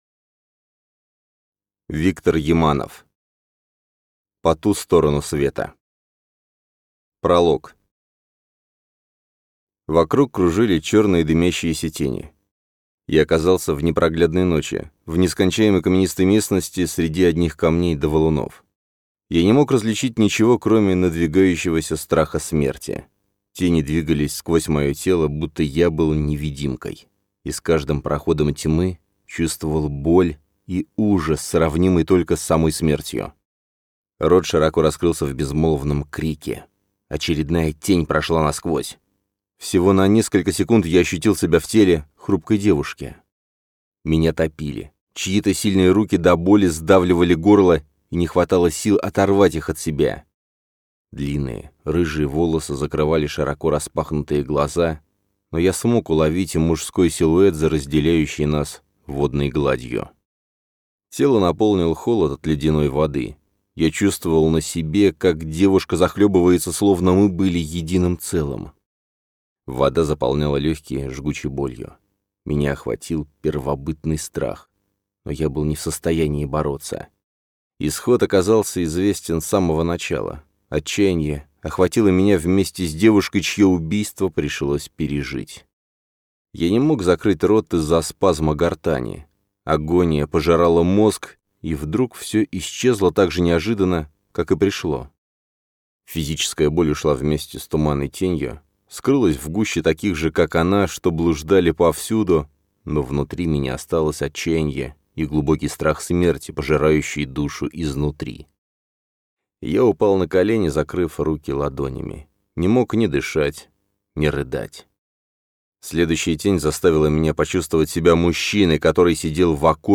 Аудиокнига По ту сторону света | Библиотека аудиокниг